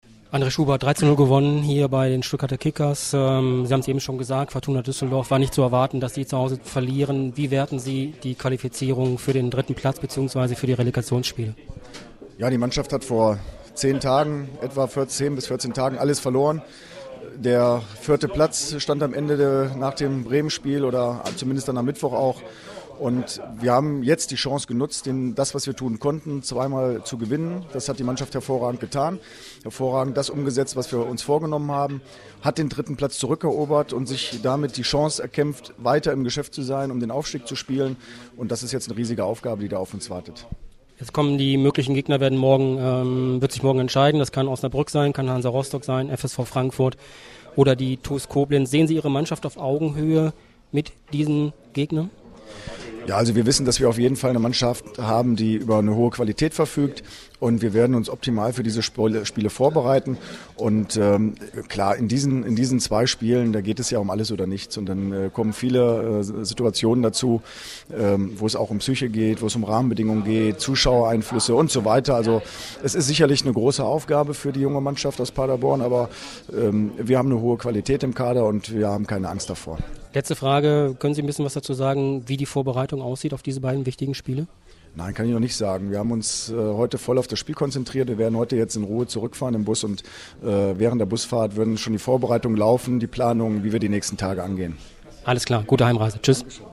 Statement